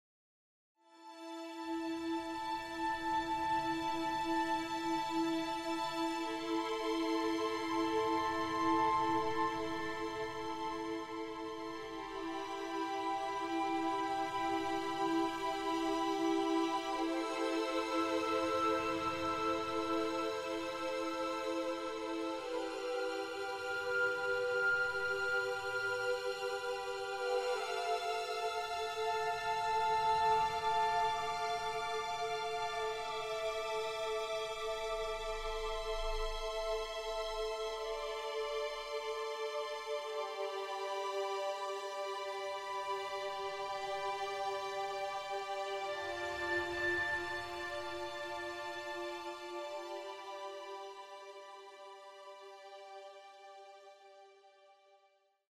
on guitar
on harmonica
dizi & shakuhachi
violins and violas
cello
keyboards